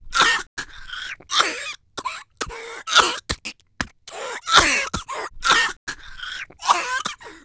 COUGH